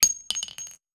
weapon_ammo_drop_03.wav